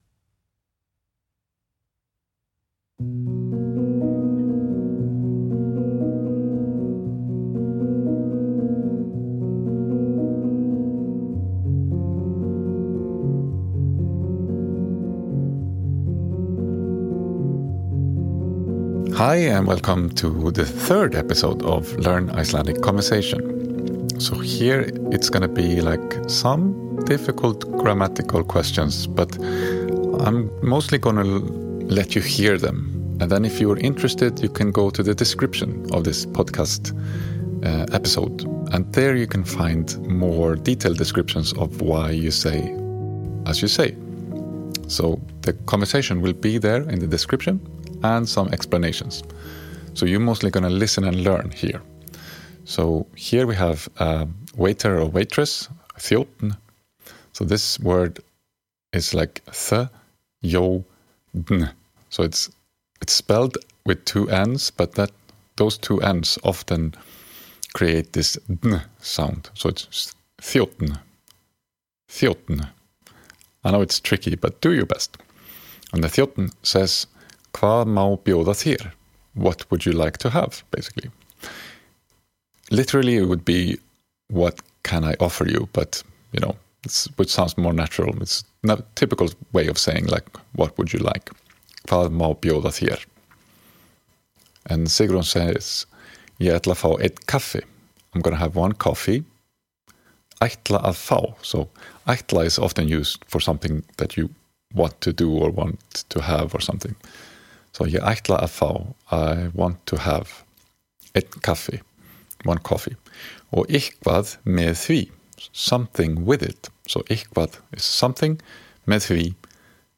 (The æ letter is pronounced like vowels in "late".)